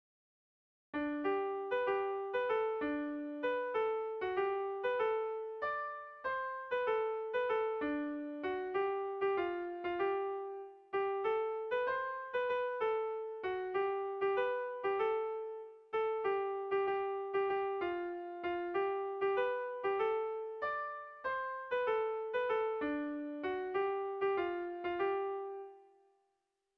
Melodías de bertsos - Ver ficha   Más información sobre esta sección
Irrizkoa
Hamarreko txikia (hg) / Bost puntuko txikia (ip)
ABDEB